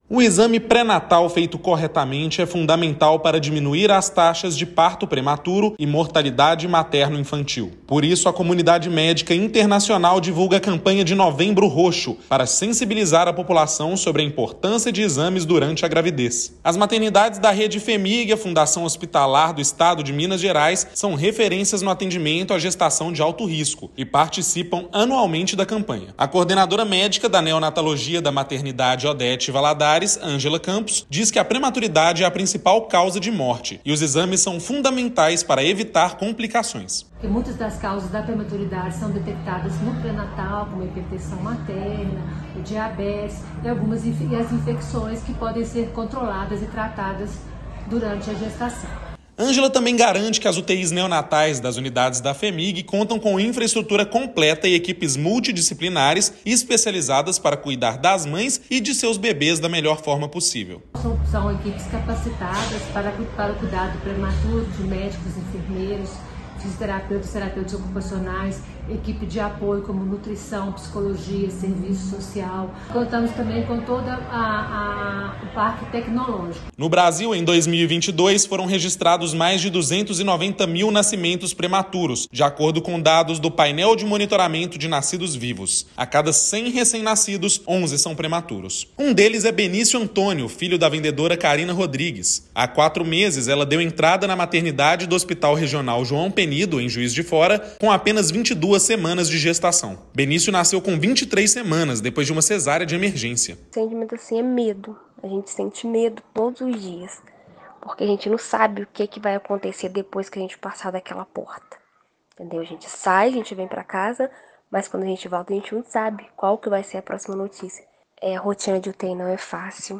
A campanha "Novembro Roxo", de alcance internacional, busca sensibilizar a população sobre a importância do pré-natal feito de forma correta e da capacitação de profissionais, visando qualificar o modelo assistencial e diminuir as taxas de parto prematuro e mortalidade materno-infantil no país. Ouça matéria de rádio.